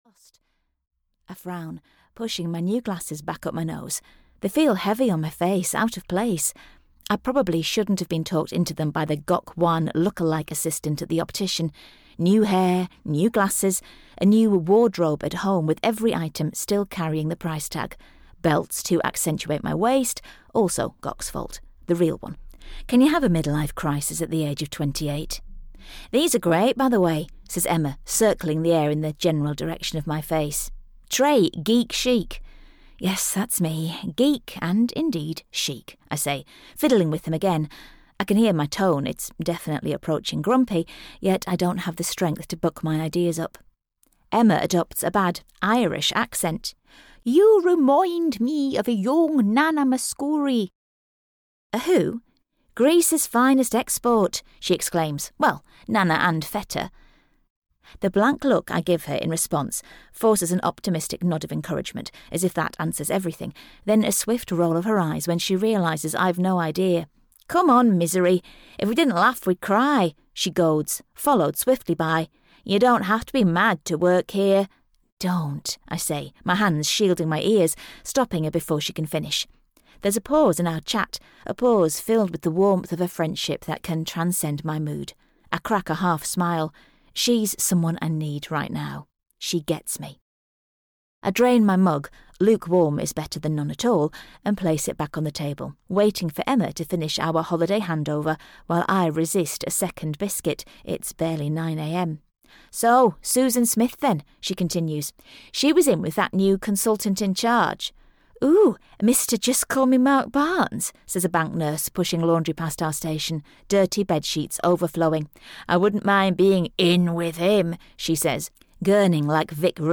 Audio knihaHow To Mend a Broken Heart (EN)
Ukázka z knihy